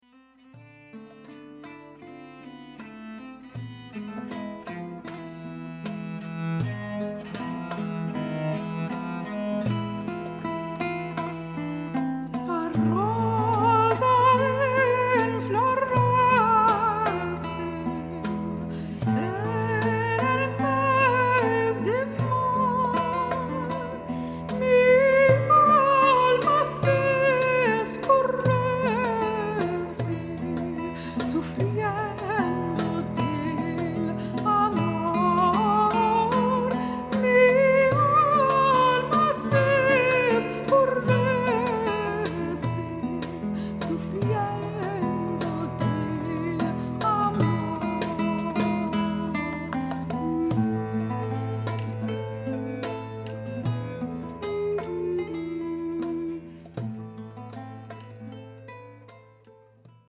Anonymous Sephardic Song